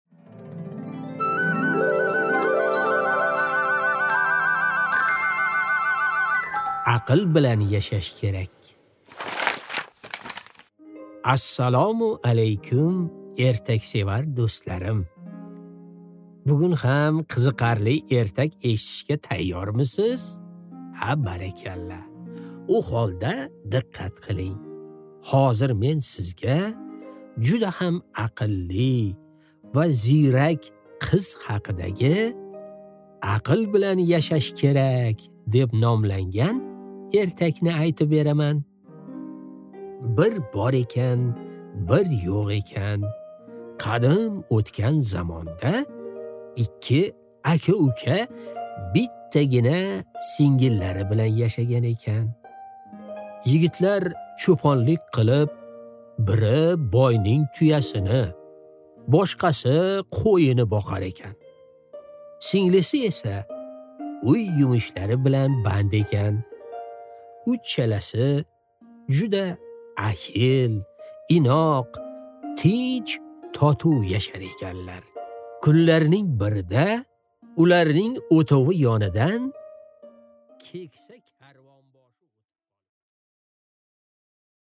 Аудиокнига Aql bilan yashash kerak | Библиотека аудиокниг